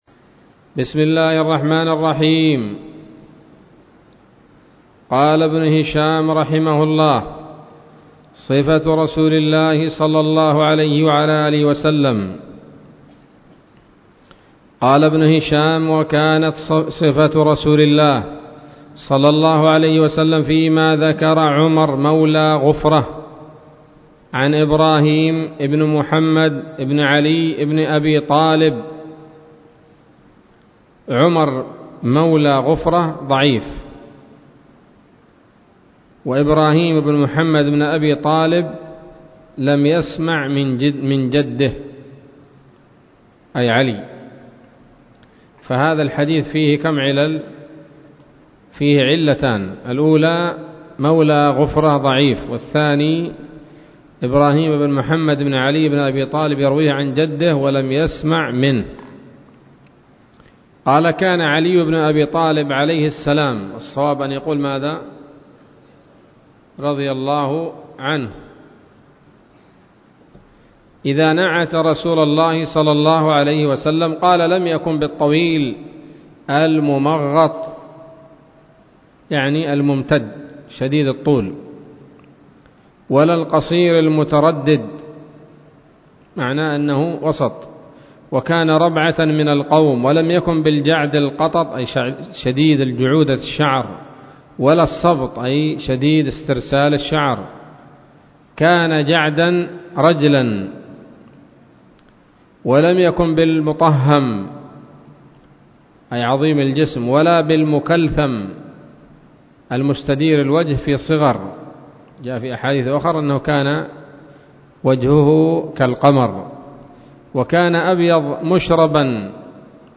الدرس التاسع والأربعون من التعليق على كتاب السيرة النبوية لابن هشام